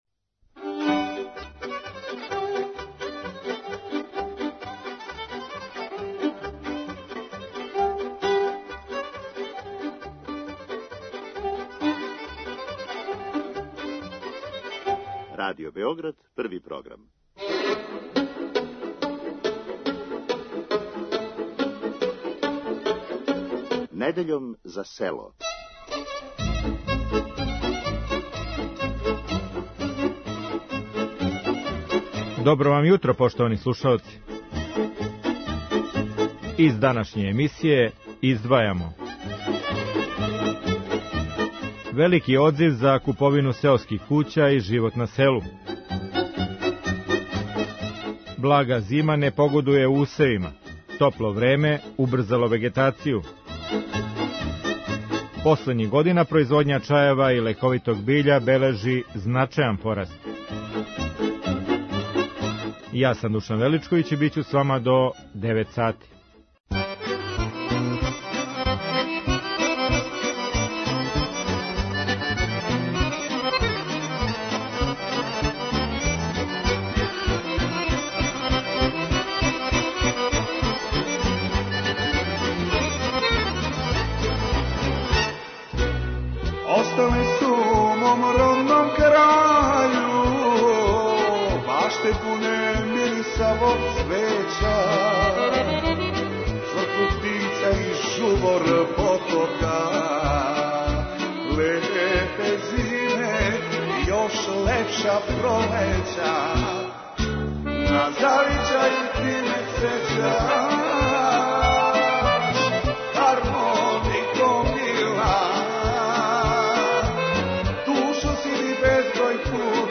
За емисију Недељом за село говоре повртари из Лесковца и Прокупља.